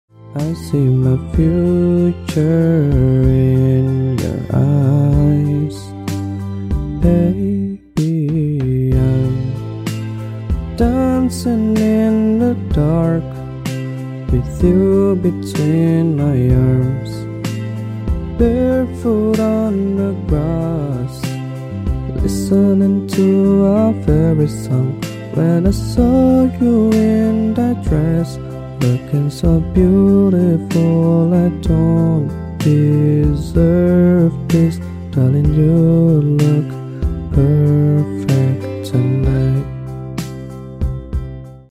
karaoke duet version